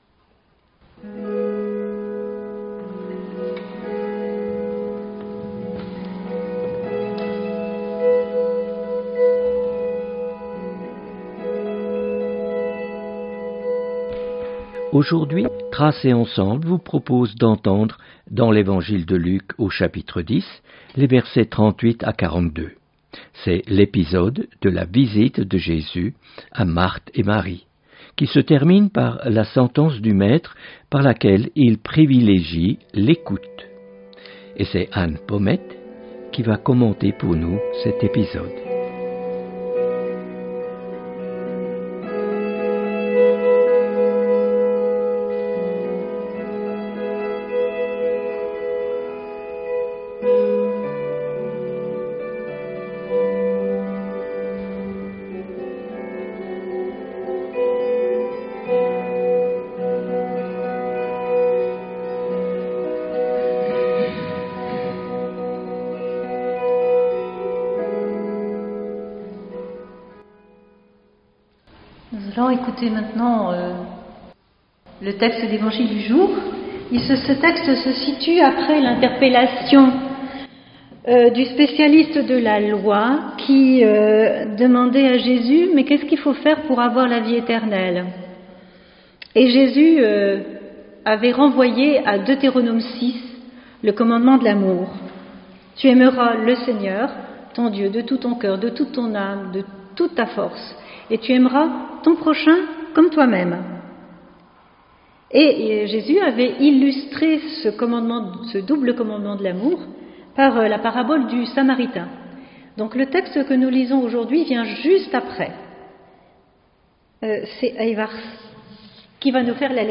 Méditation